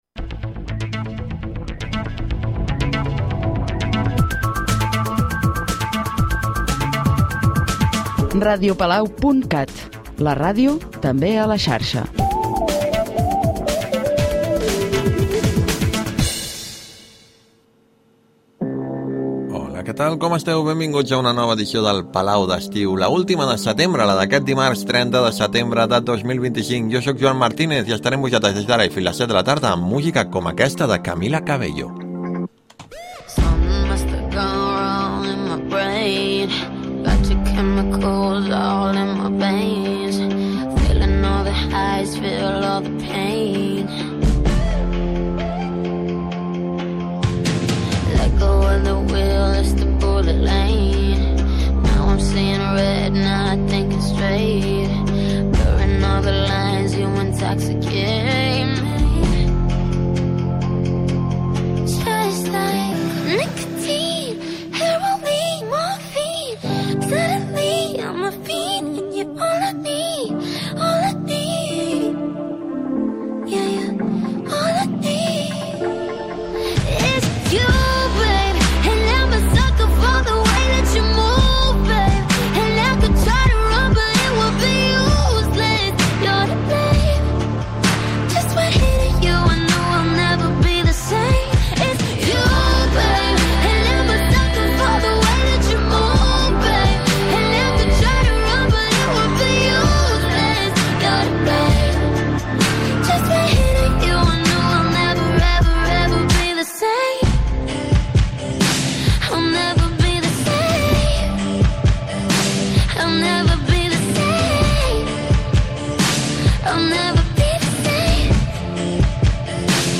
Espai musical amb apunts d'agenda amb selecció d'èxits actuals i de sempre.